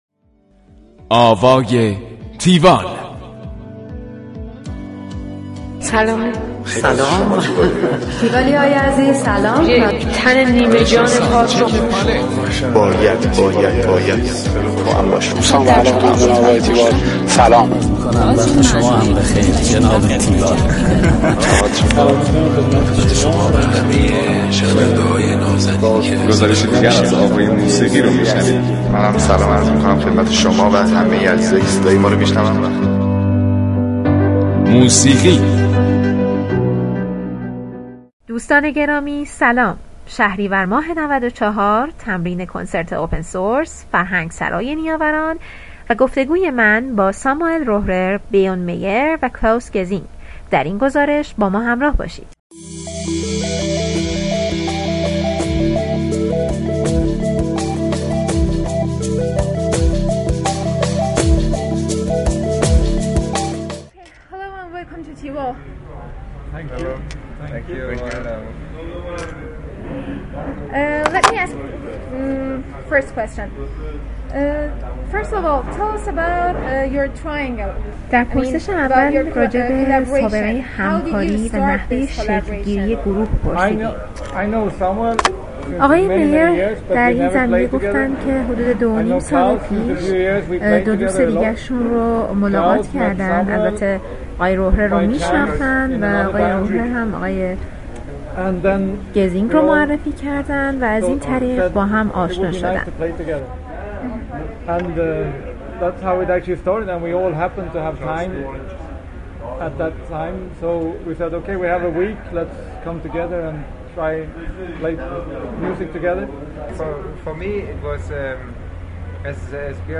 گفتگوی تیوال با اعضای گروه تریو اوپن سورس
tiwall-interview-opensource.mp3